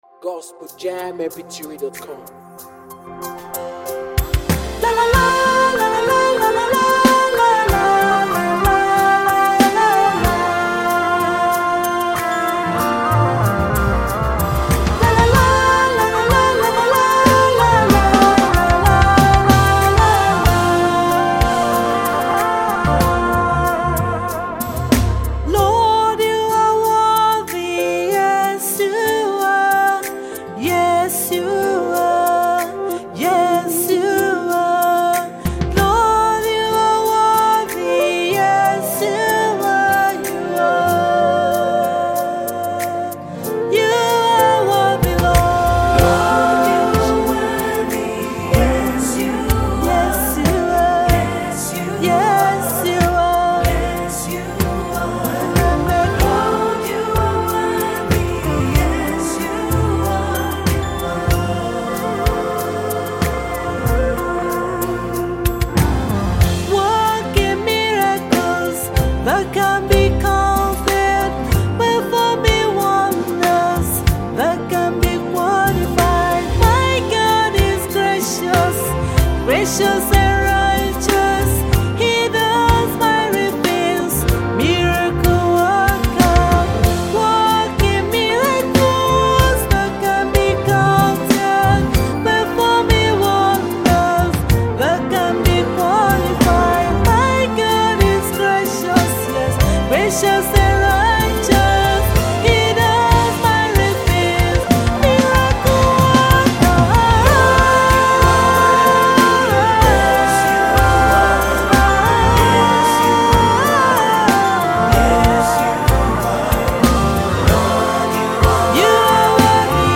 a Gospel singer with a tremendous voice